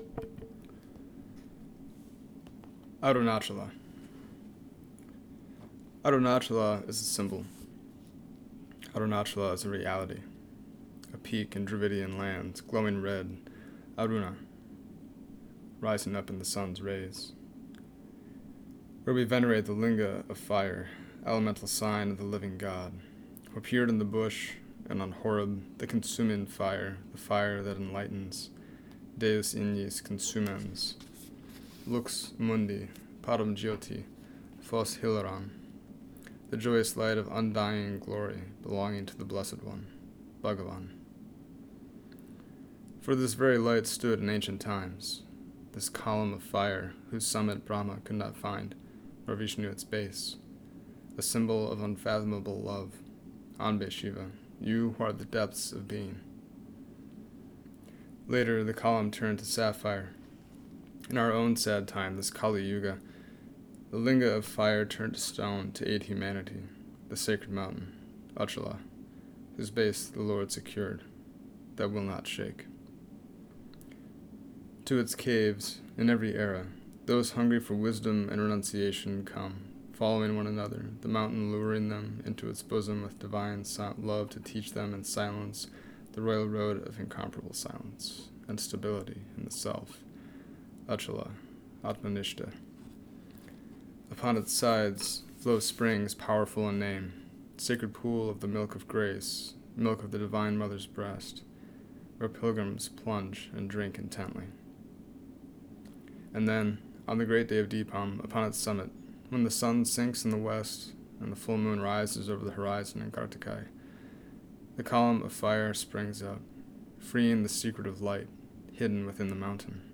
Below is a reading of my translation of Swami Abhishiktananda’s poem, and Swamiji’s own note for context.